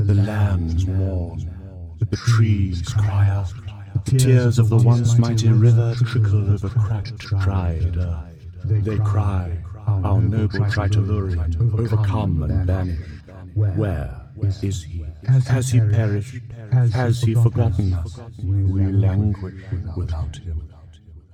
stones_lands.ogg